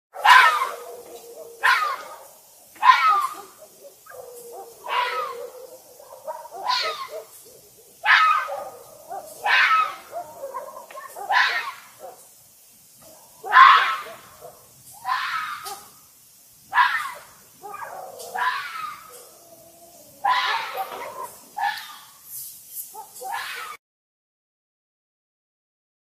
Catégorie: Animaux